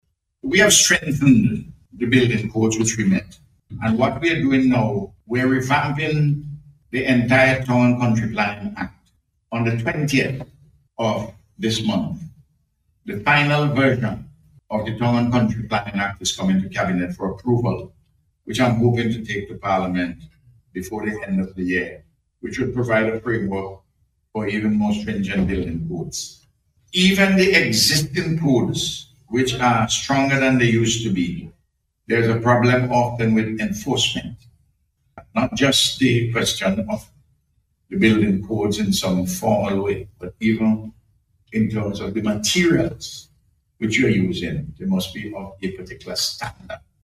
This disclosure from Prime Minister, Dr. Ralph Gonsalves, as he spoke about a range of issues at a Town Hall Meeting with Vincentians in High Wycombe, England.